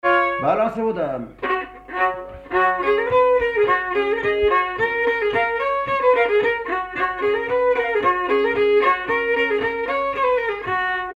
danse : marche
circonstance : bal, dancerie
Pièce musicale inédite